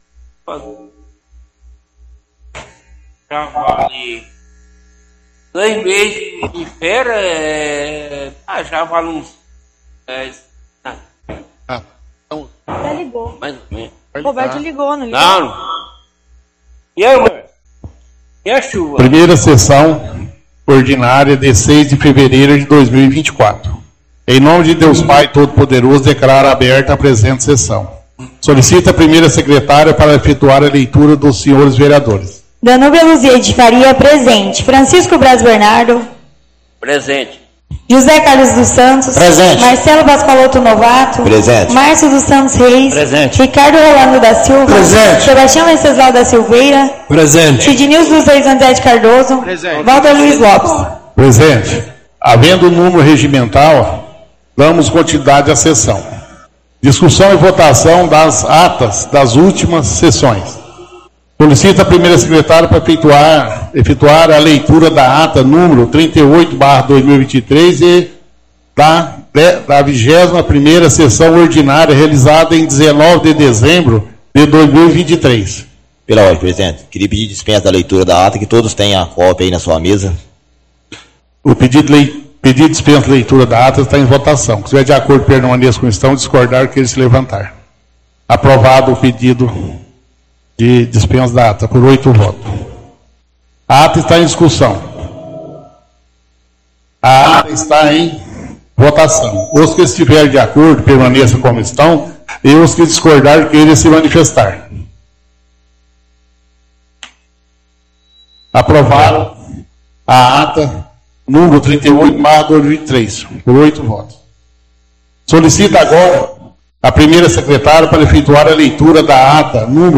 Áudio 1ª Sessão Ordinária – 06/02/2024